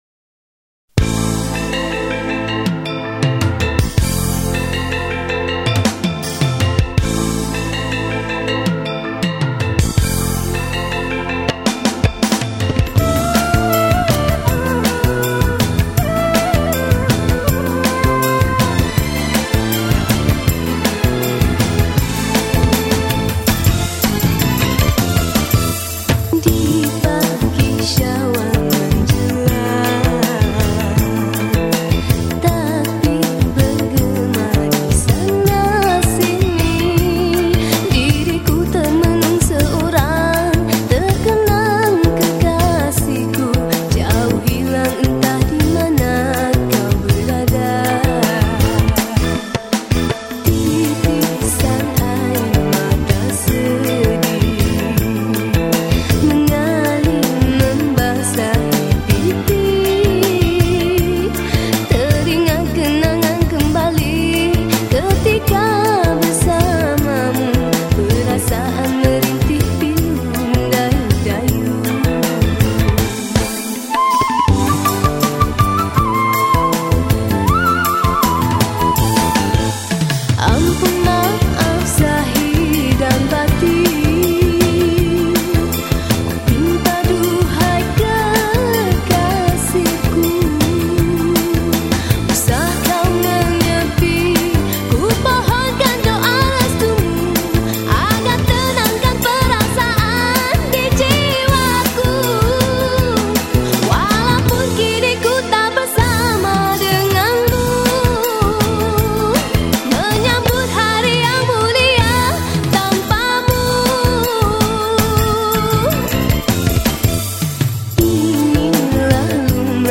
Genre: Raya.